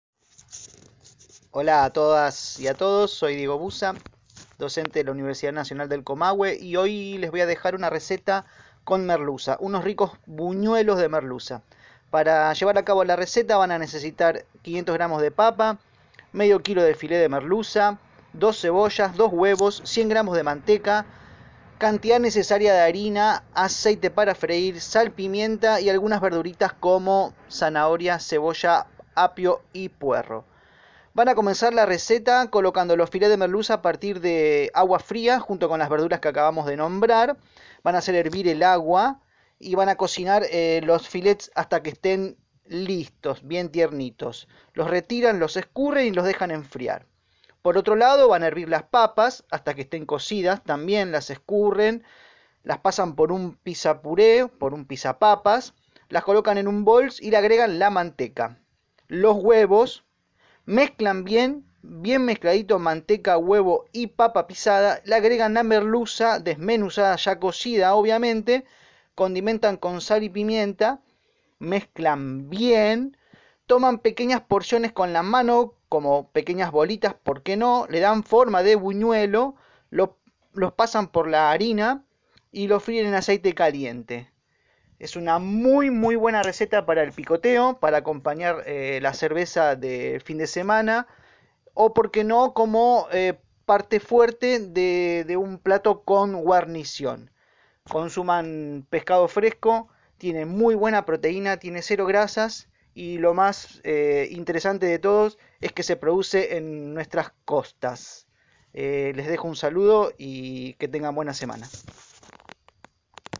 Audio receta de buñuelos de merluza